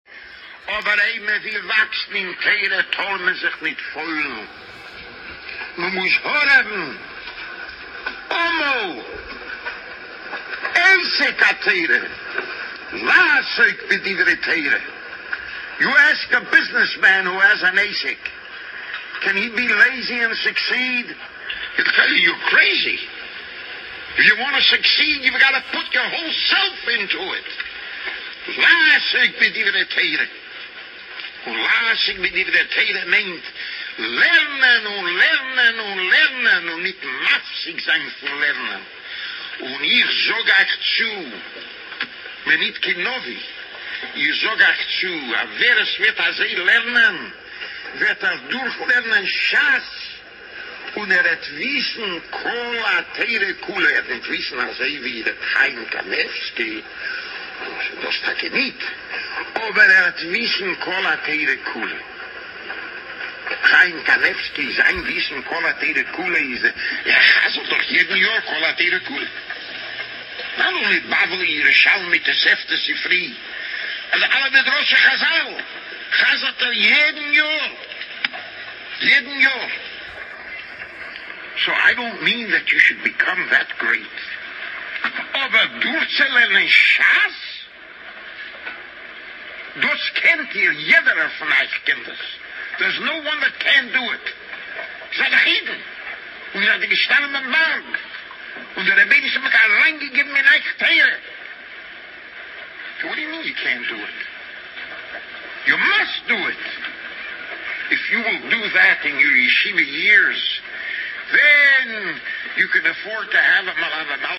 In the following audio, recorded in 1988, HaRav Mordechai Gifter zt”l speaks of Rav Chaim’s phenomenal Torah knowledge, how he would make a siyum on kol hatorah kulah ever single year, and how everyone should aspire and work towards being a small reflection of Rav Chaim’s greatness.